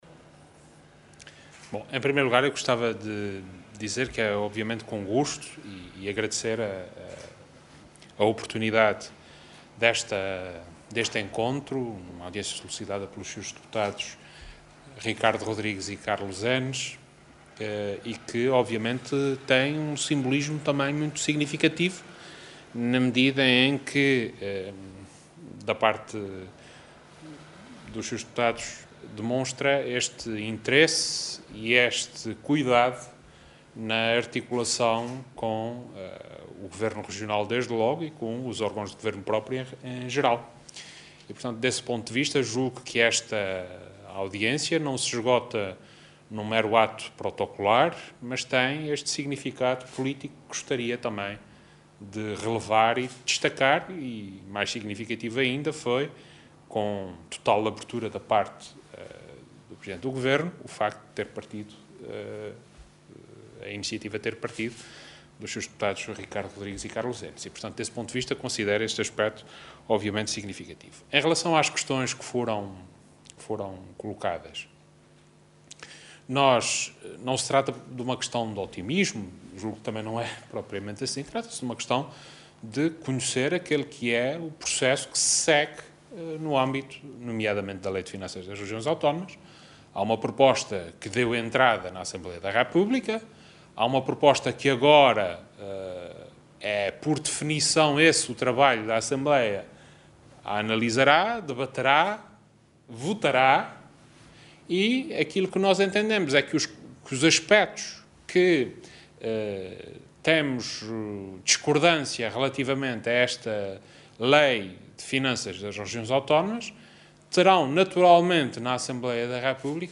“Há aspetos em que temos essa discordância, que estamos a manifestar no âmbito de contatos que têm existido com o Governo da República e agora também no âmbito da Assembleia da República será transmitido este entendimento”, afirmou Vasco Cordeiro, em declarações aos jornalistas em Ponta Delgada no final de uma audiência com os deputados eleitos pelo PS/Açores para a Assembleia da República, Ricardo Rodrigues e Carlos Enes.